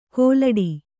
♪ kōlaḍi